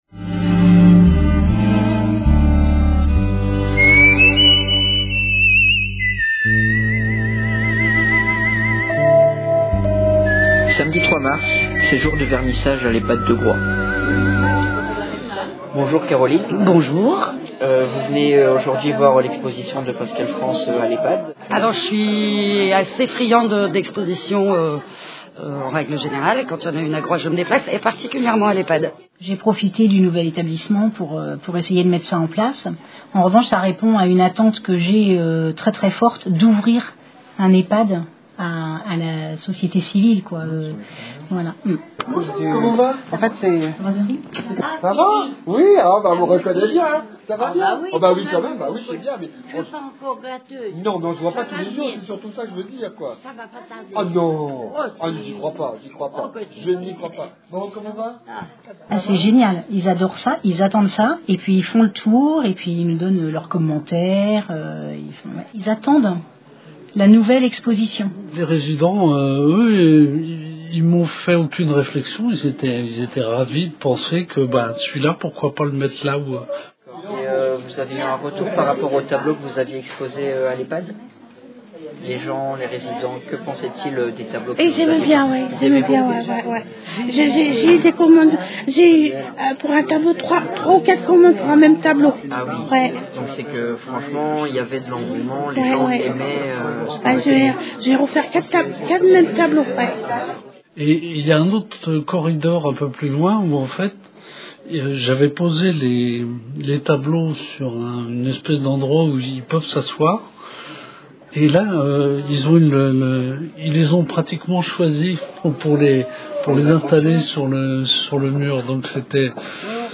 Un instant de vie collective offert au nouvel l’EHPAD, lieu d’accueil bien adapté pour recevoir expositions et animations diverses.
Chouette reportage ! du boulot de pro, bravo !
ehpad-de-Groix.mp3